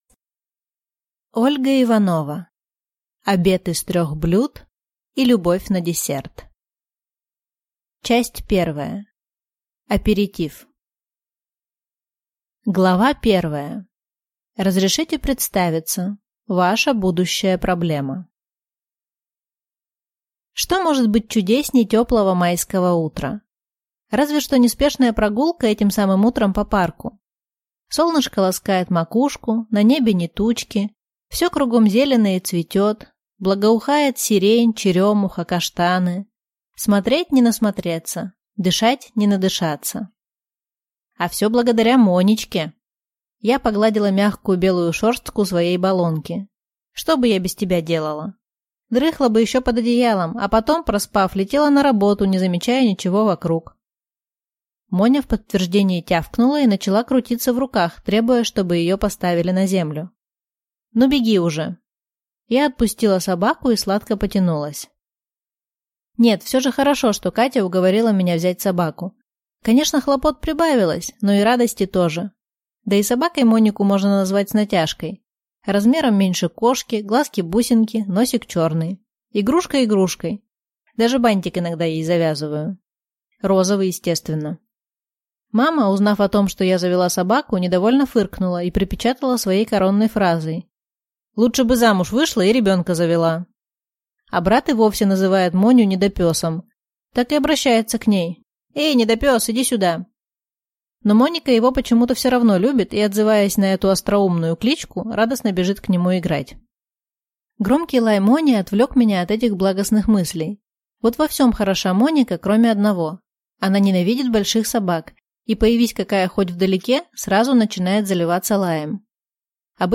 Аудиокнига Обед из трех блюд и любовь на десерт | Библиотека аудиокниг